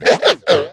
mech_mike_kill_vo_04.ogg